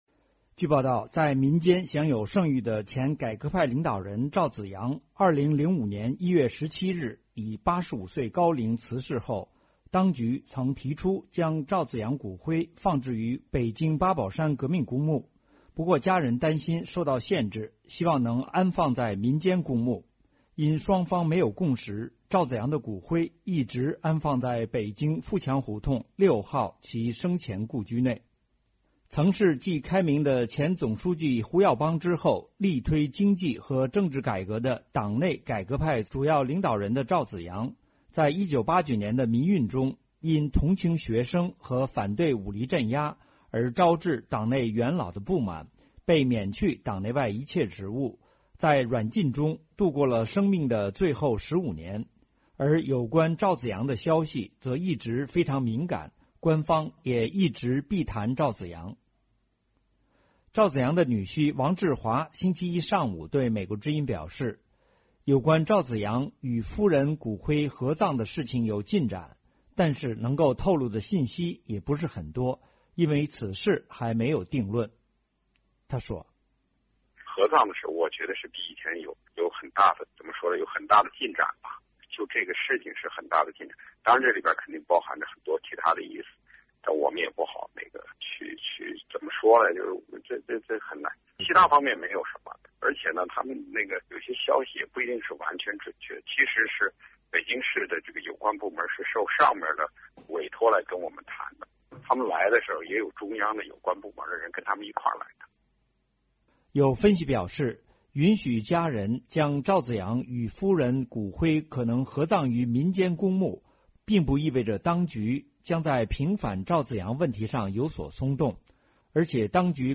但是他的语气听起来比较乐观。